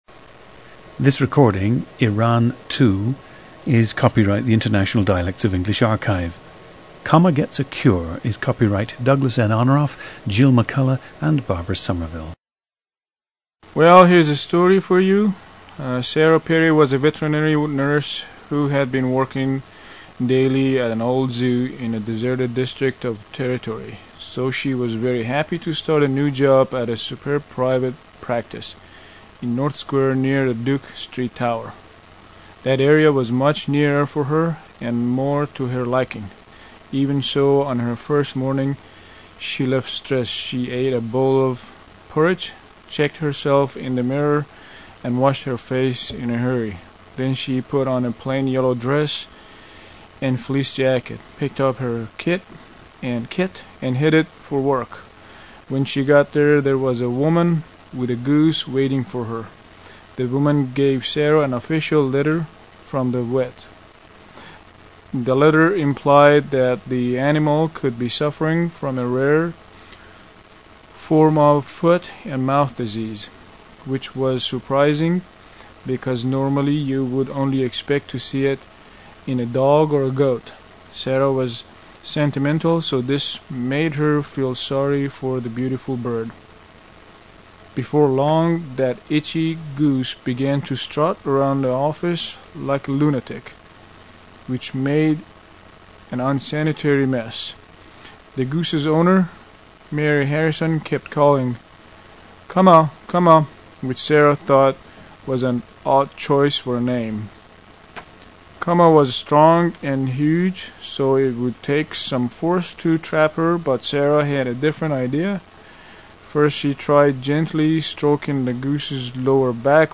Listen to Iran 2, a 43-year-old man from Tehran, Iran, who has also lived in the United States.
GENDER: male
At the time of the interview, subject had been living in the United States for 25 years.
• Recordings of accent/dialect speakers from the region you select.
The recordings average four minutes in length and feature both the reading of one of two standard passages, and some unscripted speech.